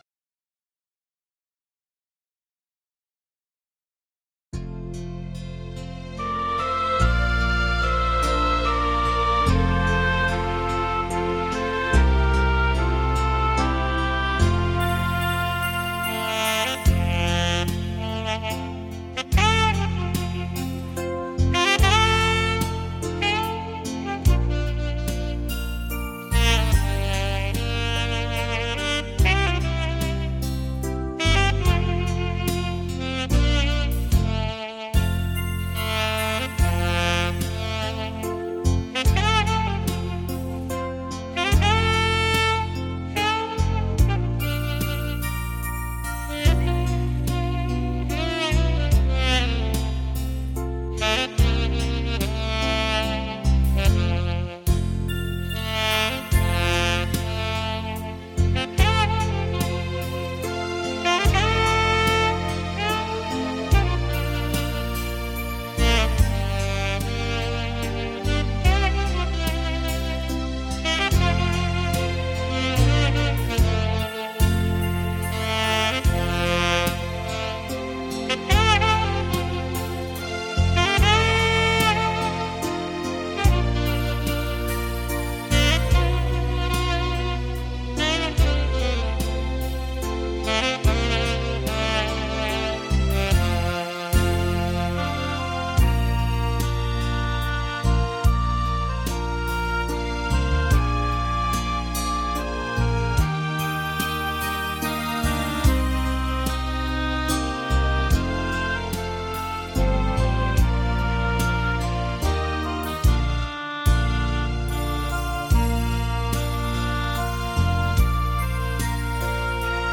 本CD母带经由美国太平洋微音公司HDCD贰型处理器处理
萨克斯、浪漫风情的完美代言人。
萨克斯风的独特磁场所舒放的温柔优雅旋律,就似娇羞的少女